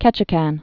(kĕchĭ-kăn)